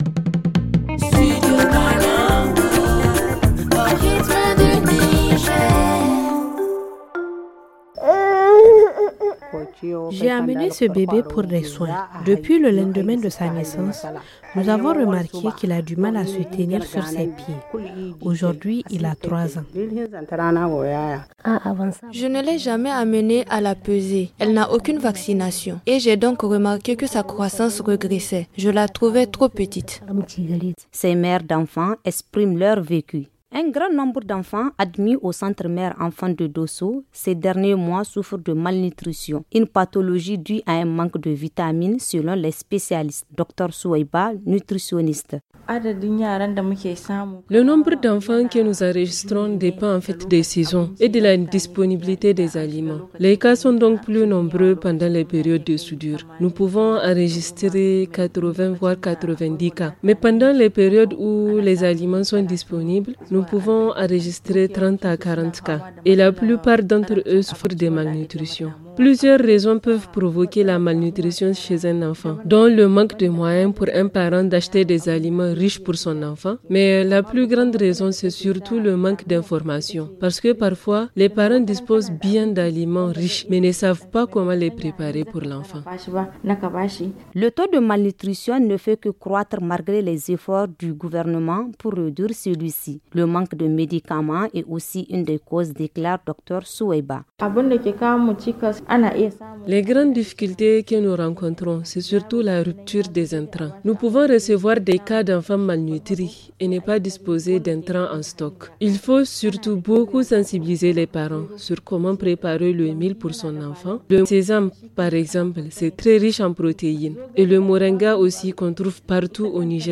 Suivons ce reportage